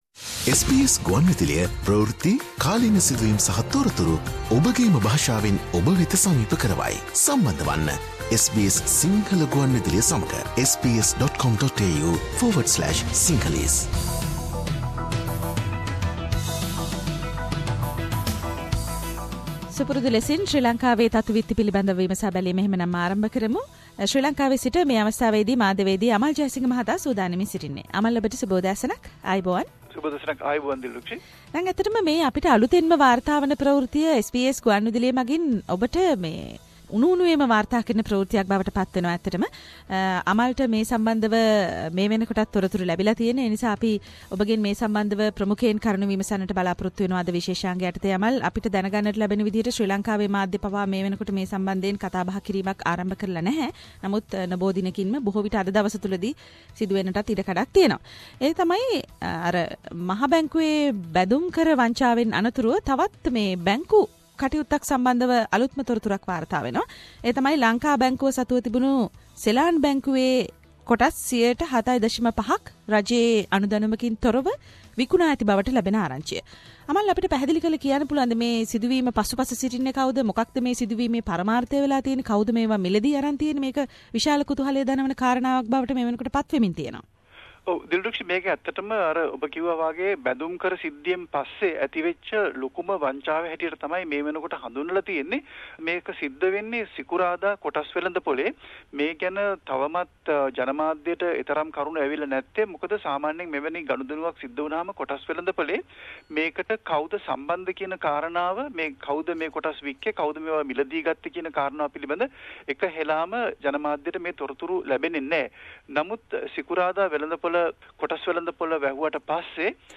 Braking news : BOC illegally sold of it's own Saylan Bank shares - Weekly news highlights from Sri Lanka